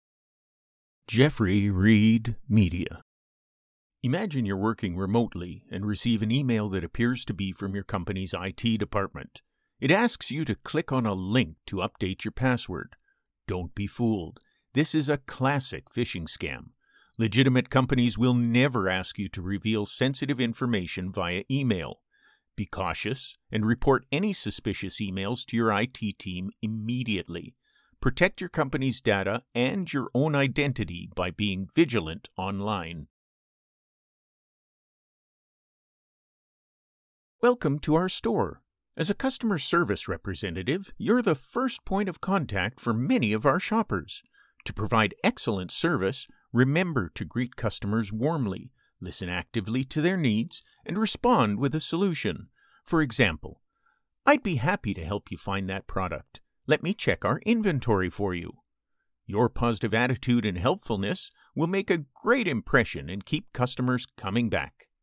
E-Learning Demo
Educational and training content delivery
e-learning.mp3